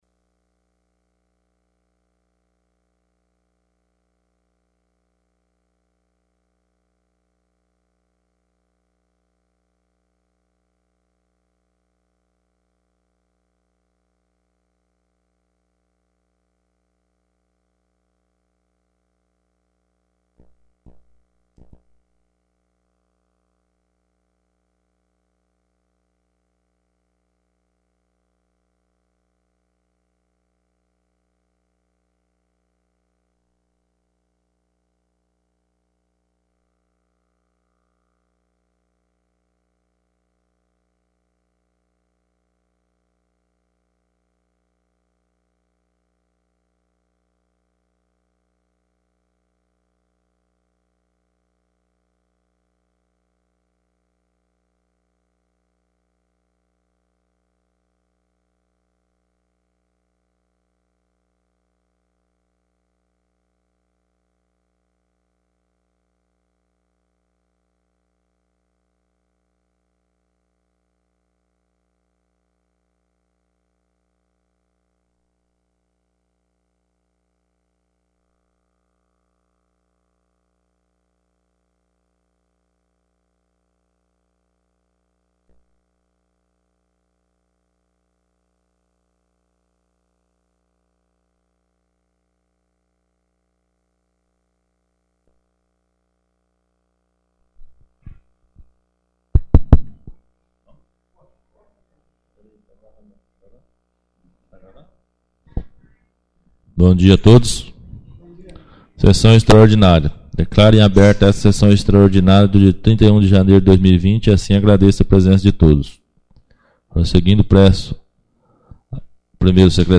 1ª Extraordinária da 4ª Sessão Legislativa da 11ª Legislatura